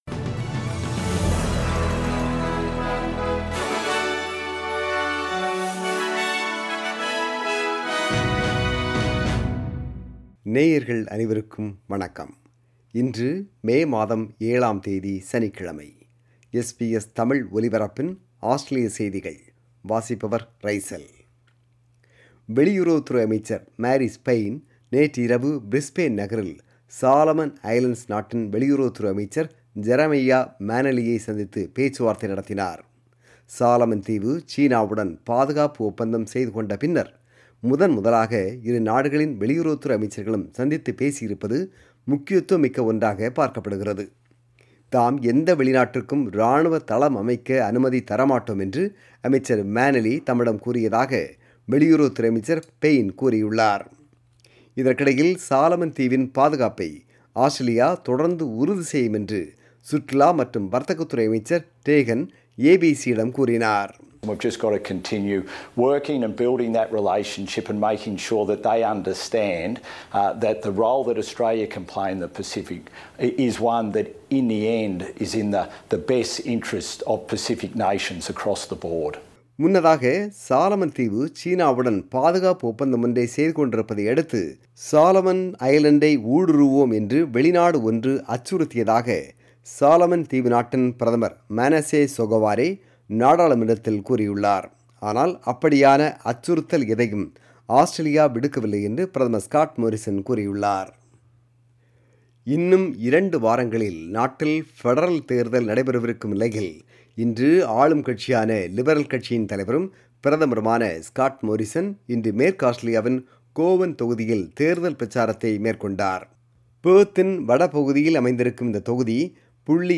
Australian News: 7 May 2022 – Saturday